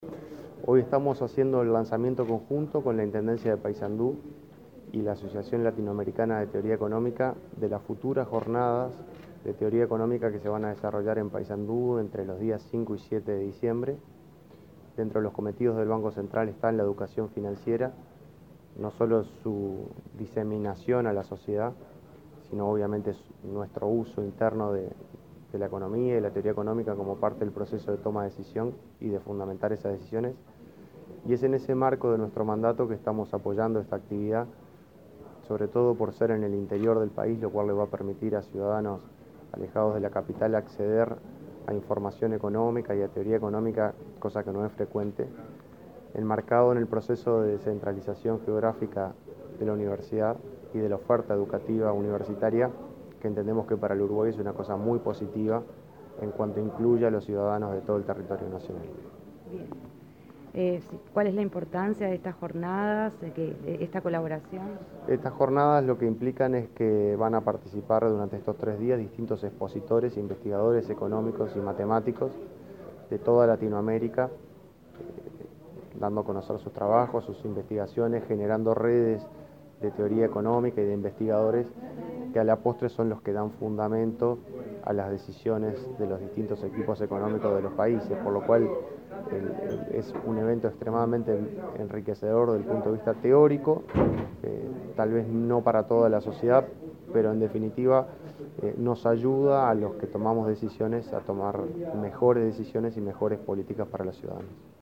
Declaraciones del vicepresidente del BCU, Washington Ribeiro